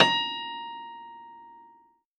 53v-pno10-A3.aif